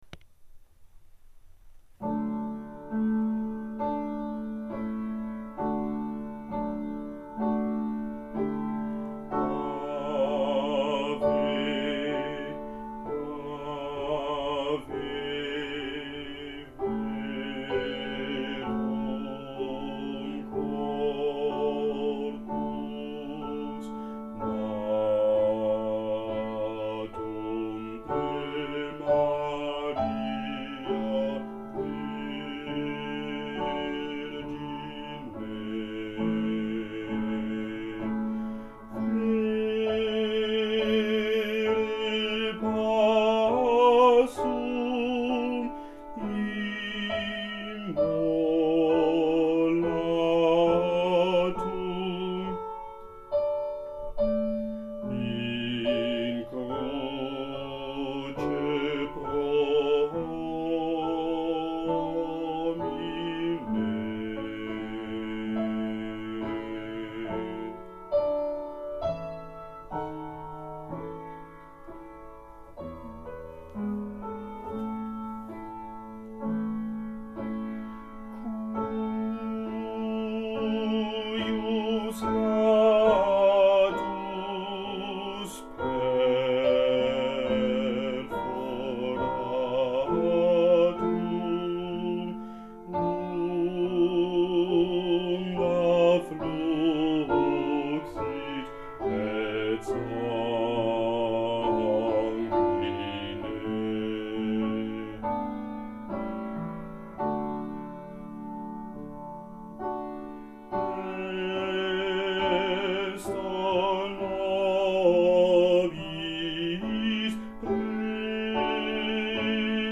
Musica SACRA Bajos
Ave-Verum-Bajos.mp3